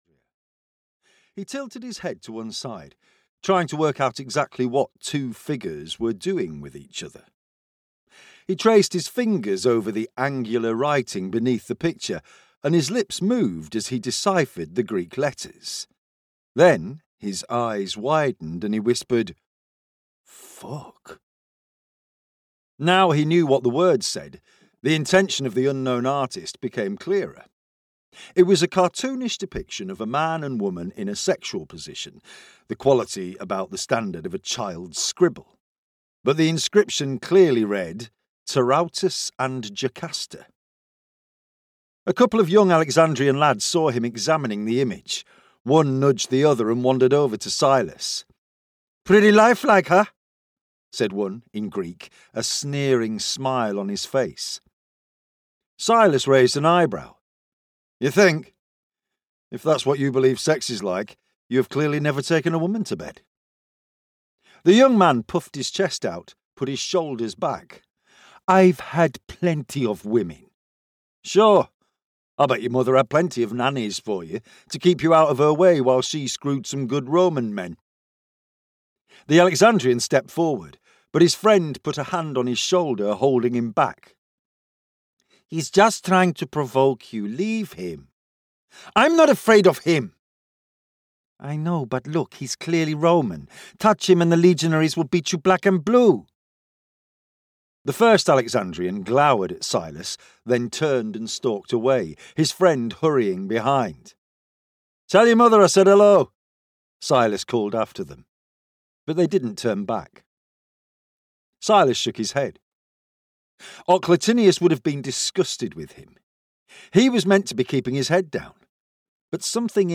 Emperor's Fate (EN) audiokniha
Ukázka z knihy